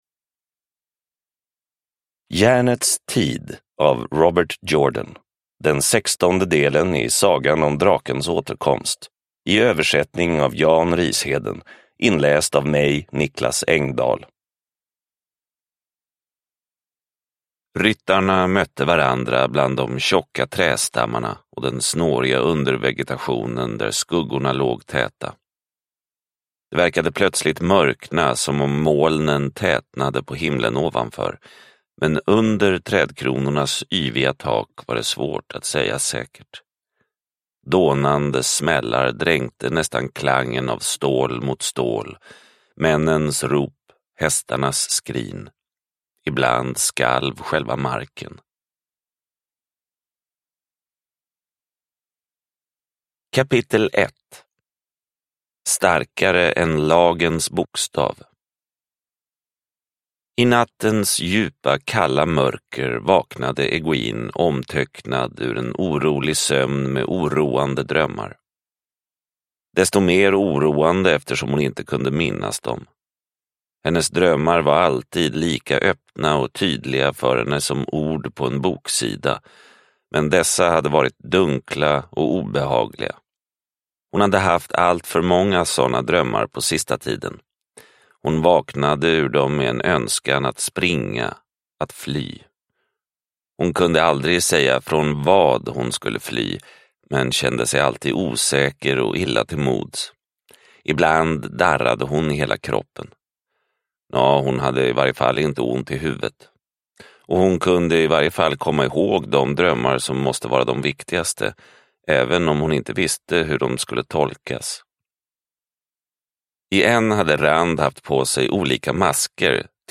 Järnets tid – Ljudbok – Laddas ner